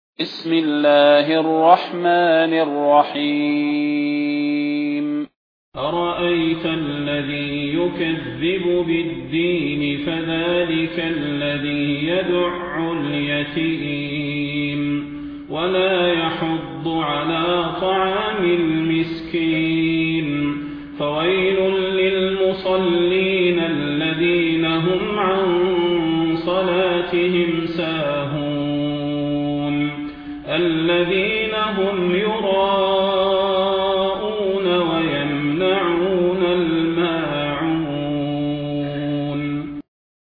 فضيلة الشيخ د. صلاح بن محمد البدير
المكان: المسجد النبوي الشيخ: فضيلة الشيخ د. صلاح بن محمد البدير فضيلة الشيخ د. صلاح بن محمد البدير الماعون